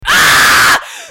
Sound Effects
Big Aaaaaaaaaah!